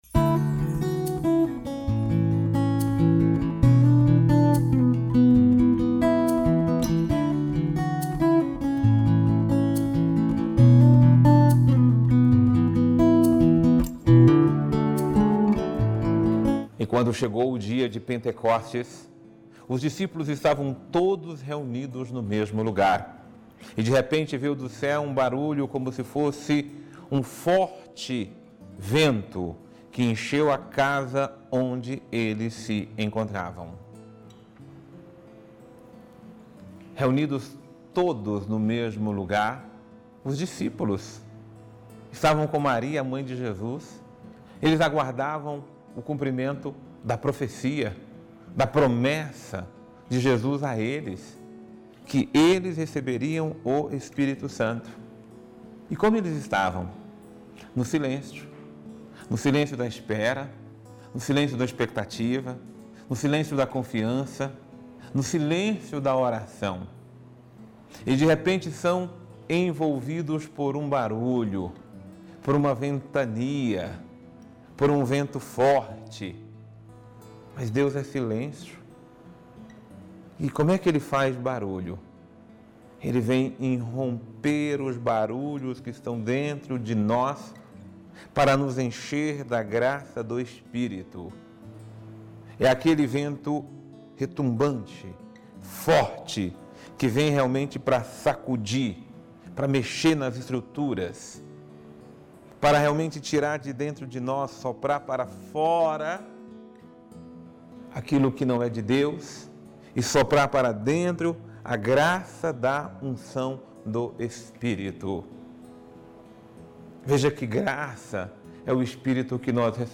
Homilia diária | Sejamos movidos pela graça do Espírito Santo